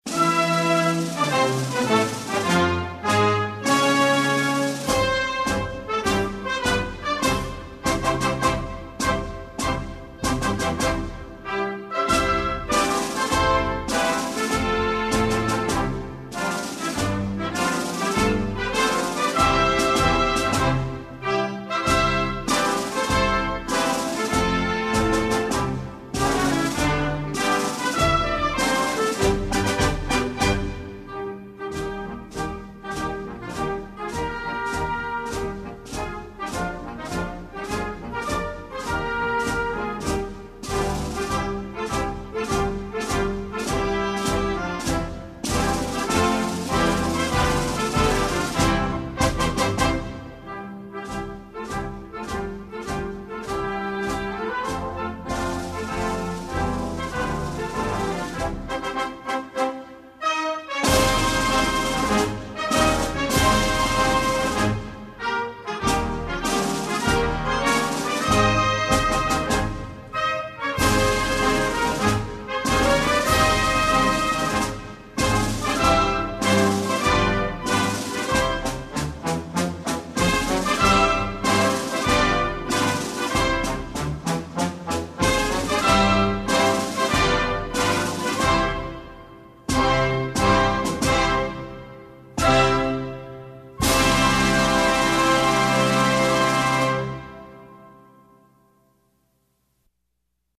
Himno_Nacional_de_Bolivia_instrumental.mp3